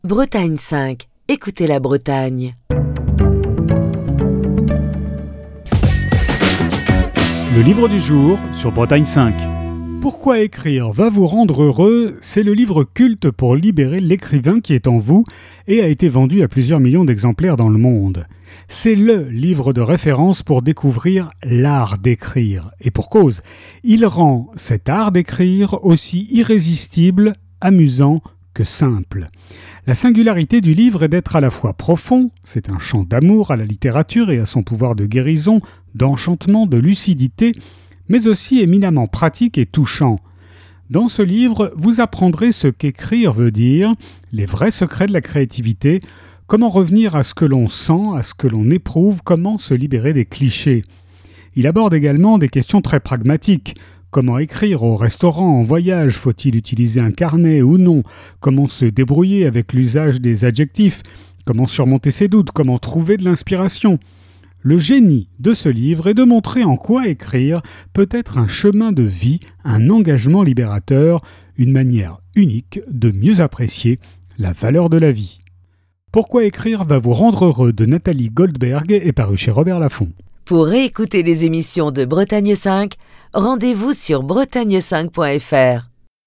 Chronique du 22 octobre 2021.